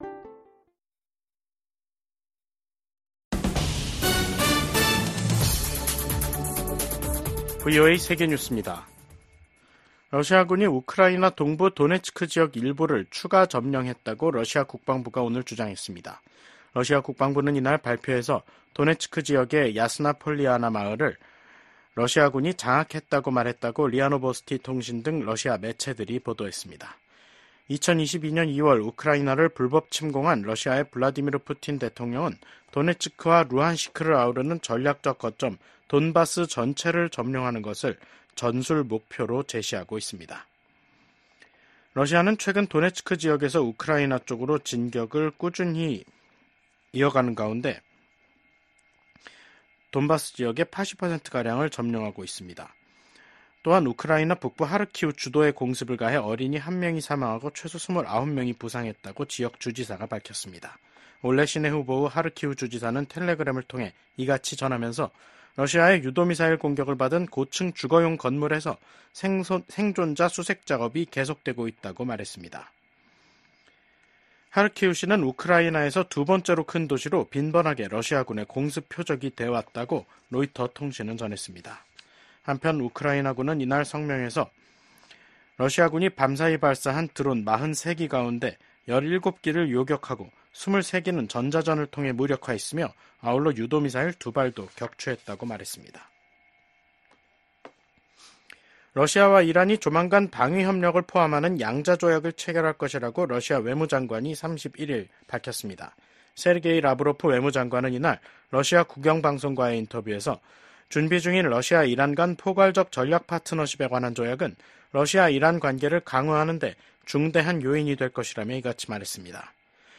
VOA 한국어 간판 뉴스 프로그램 '뉴스 투데이', 2024년 10월 31일 3부 방송입니다. 북한이 미국 대선을 닷새 앞두고 동해상으로 대륙간탄도미사일(ICBM)을 발사했습니다. 미국 백악관이 북한의 대륙간탄도미사일(ICBM) 발사를 규탄하고 북한에 불안정한 행동을 중단할 것을 촉구했습니다. 미국과 한국의 국방장관이 북한군의 러시아 파병을 한 목소리로 강력하게 규탄했습니다.